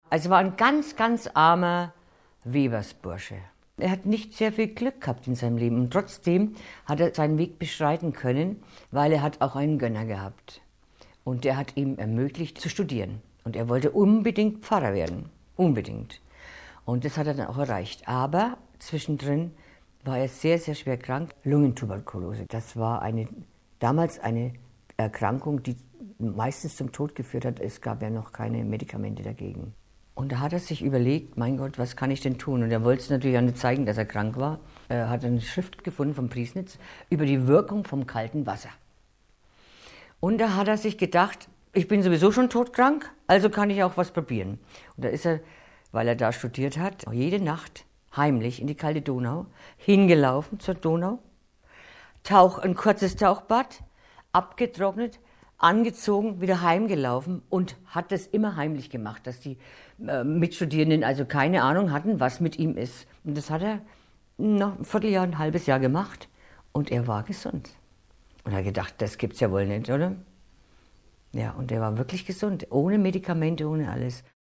begann 1966 ihre Laufbahn als Kneipp Bademeisterin.